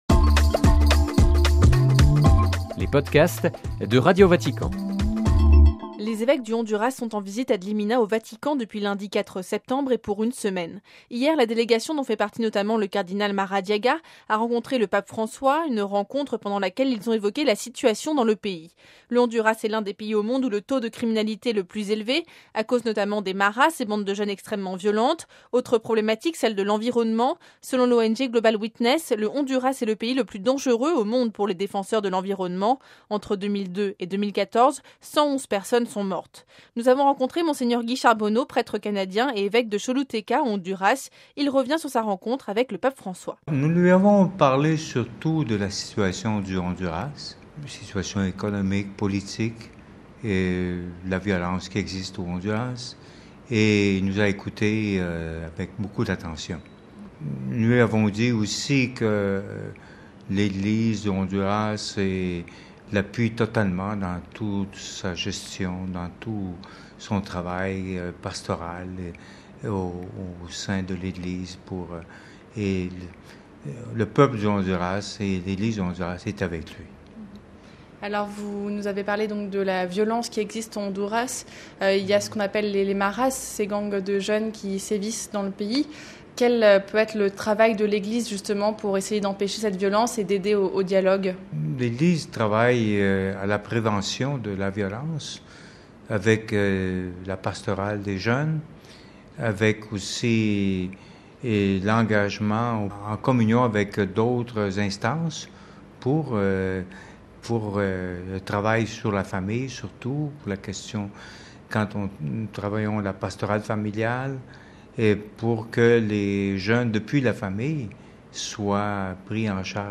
(RV) Entretien - Les évêques du Honduras sont en visite ad limina au Vatican depuis lundi 4 septembre 2017, et pour une semaine.
Nous avons rencontré Mgr Guy Charbonneau, prêtre canadien, et évêque de Choluteca au Honduras.